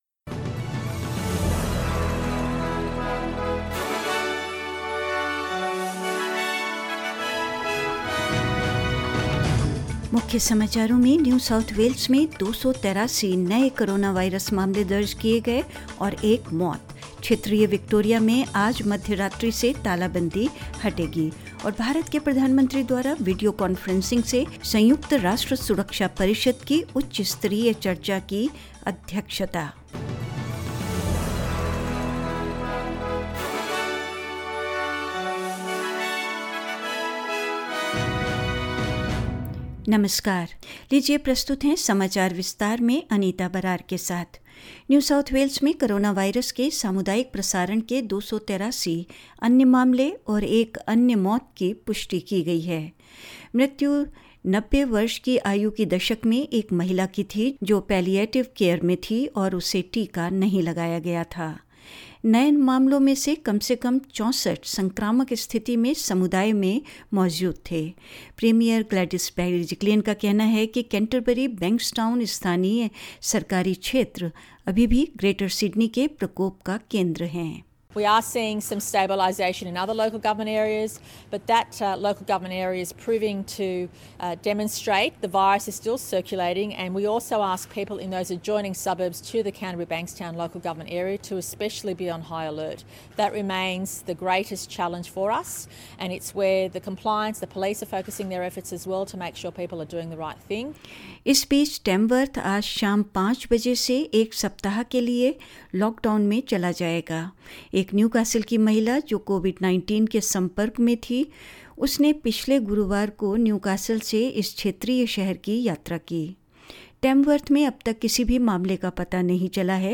In this latest SBS Hindi News bulletin of Australia and India: New South Wales records 283 new coronavirus cases and one death; Lockdown to lift in regional Victoria at midnight; India’s Prime Minister Narendra Modi will chair UN Security Council’s high level virtual debate (today) on Maritime Security and more.